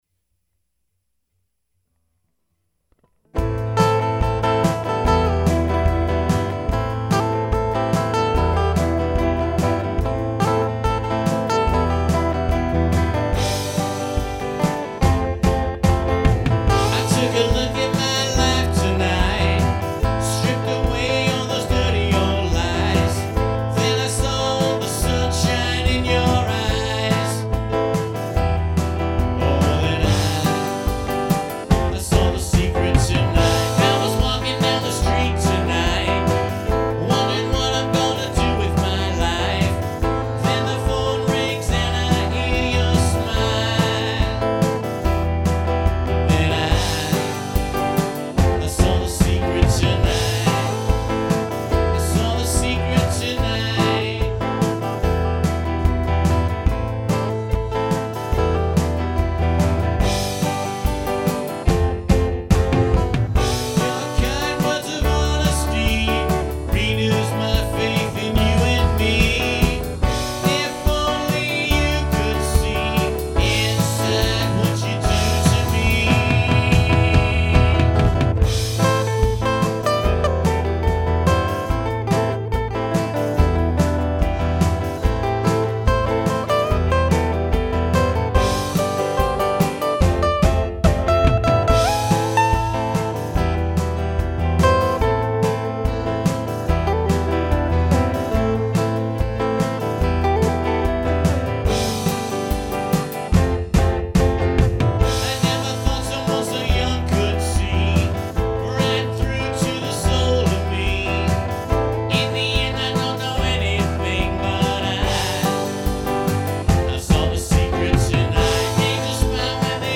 All instruments are played by me without any click tracks.
That's why they sound so rough.